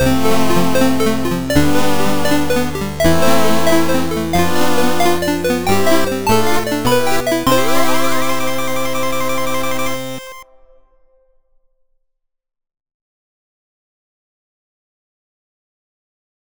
pull-sword.wav